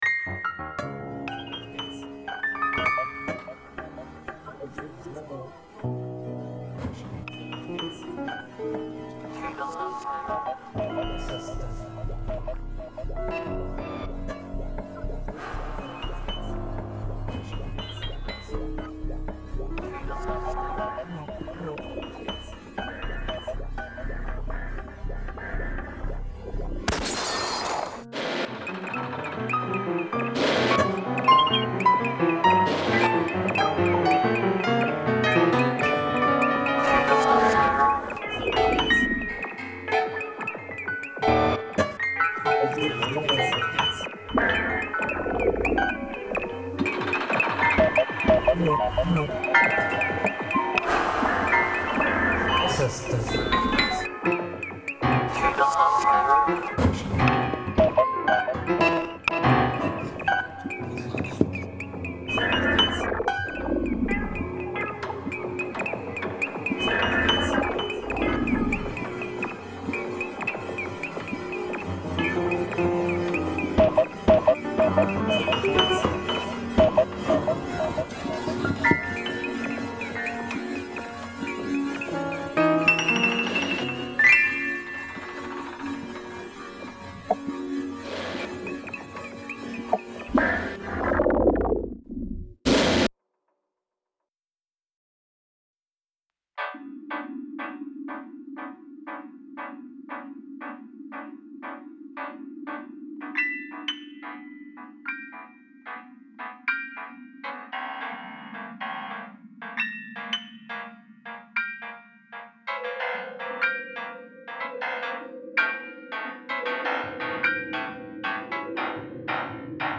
für Midiflügel, Computer und Liveelektronik
Eilig, ruhelos, zwiespältig und sicher auch nachdenklich